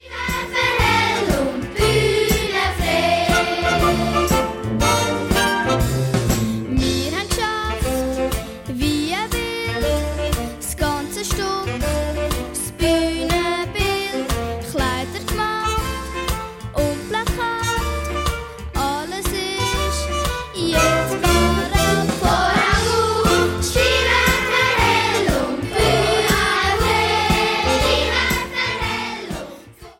Mundartlieder für Chinderchile